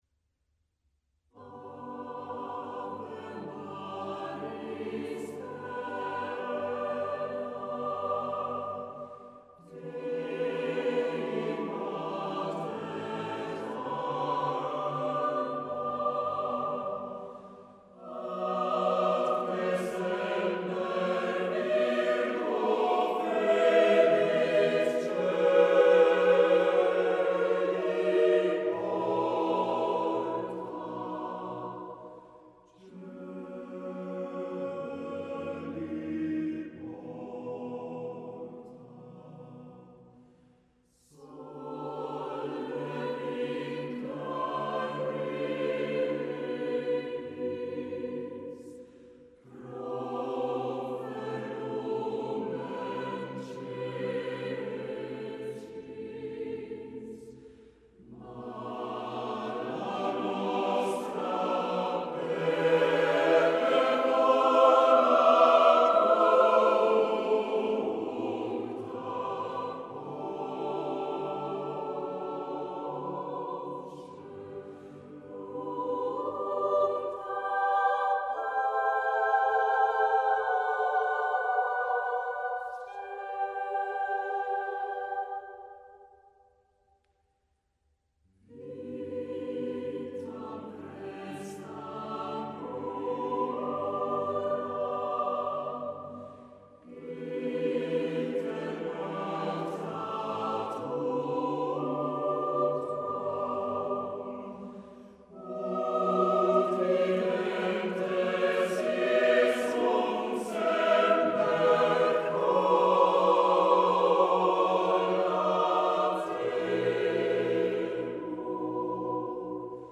Ave maris stella, blandet kor sort fjer ikon